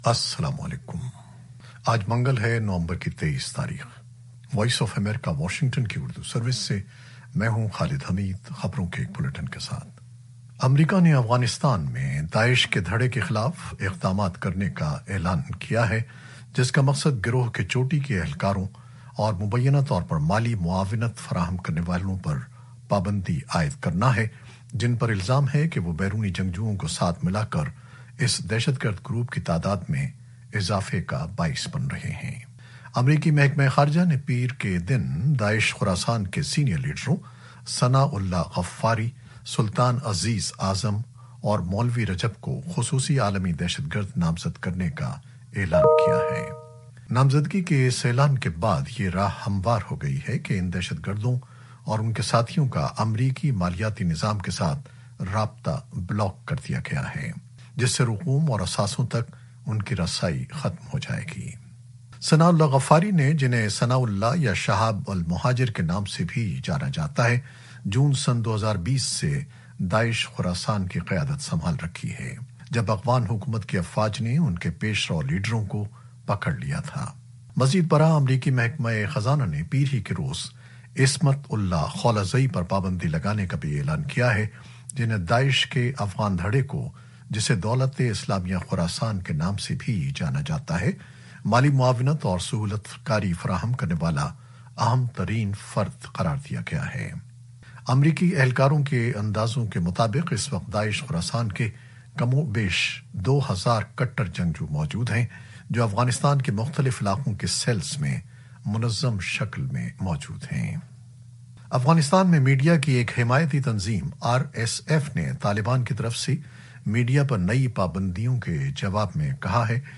نیوز بلیٹن 2021-23-11